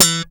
Index of /90_sSampleCDs/East Collexion - Bass S3000/Partition A/SLAP BASS-B